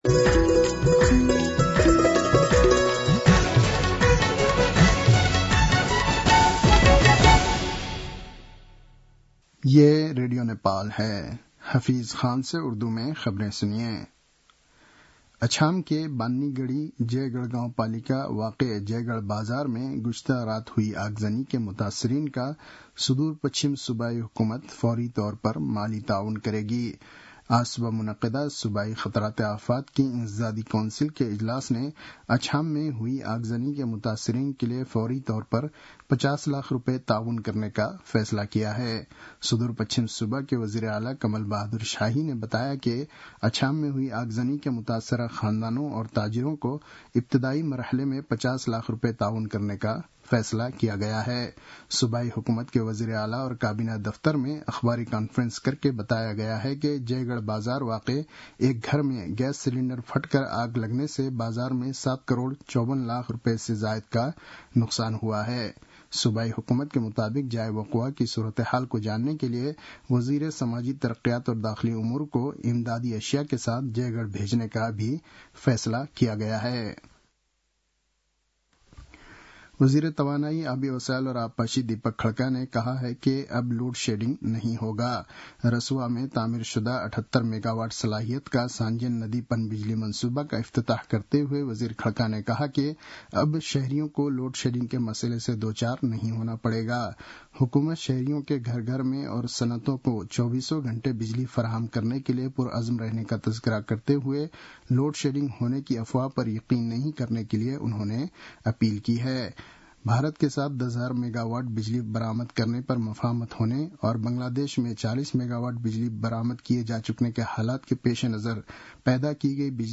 उर्दु भाषामा समाचार : २७ चैत , २०८१